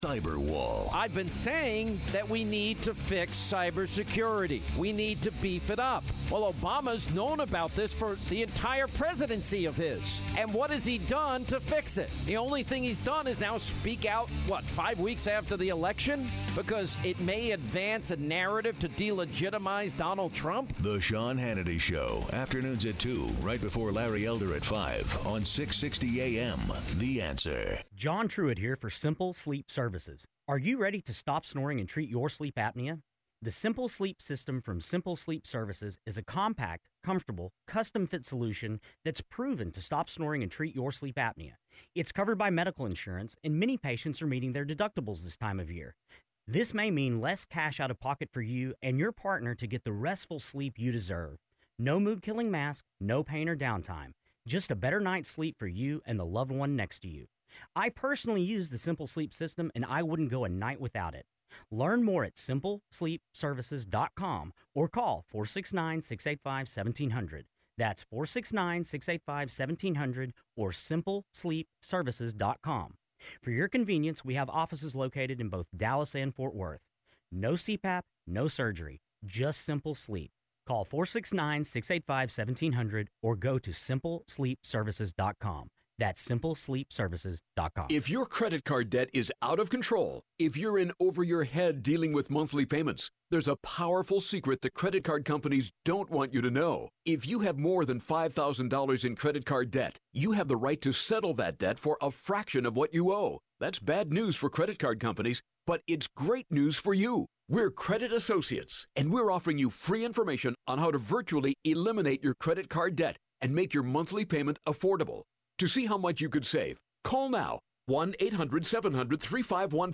The Electoral College Vote; Interview with Lt. Col. Allen West